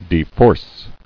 [de·force]